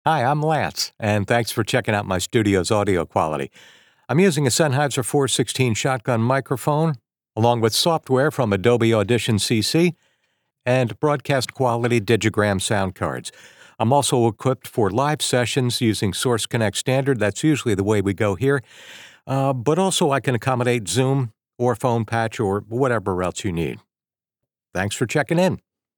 Male
The vibe is Warm, Friendly, Familiar, and Trustworthy, with just enough Edge to keep it interesting.
Studio Quality Sample
Microphone, Software & Sound